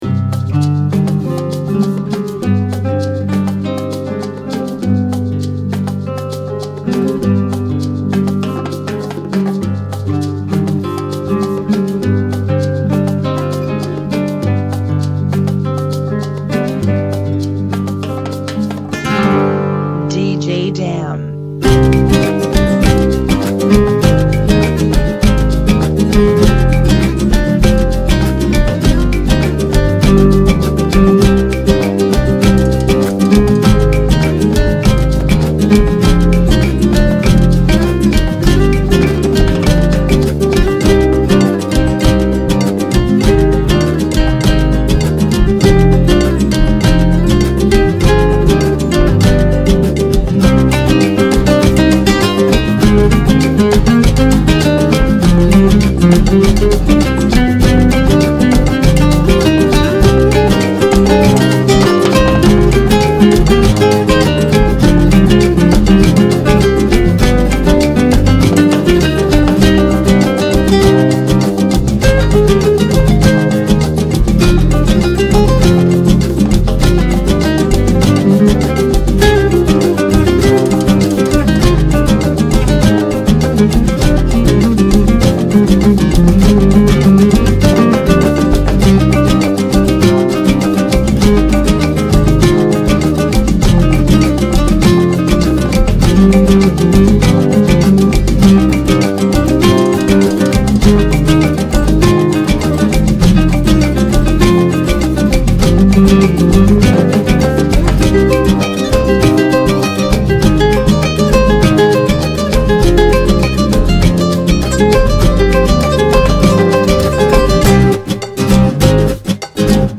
100 BPM
Genre: Salsa Remix